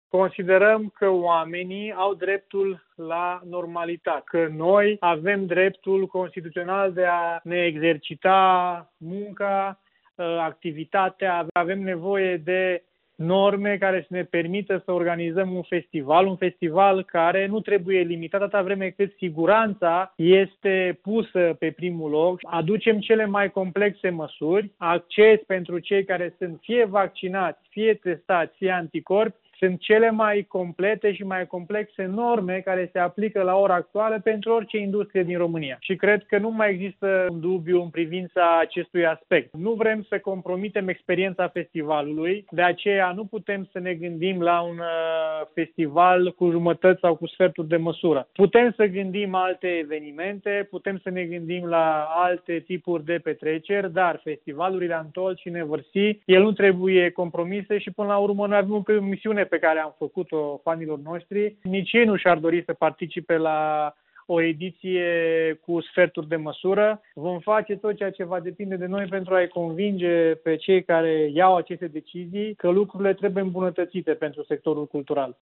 Declarația în format AUDIO, mai jos: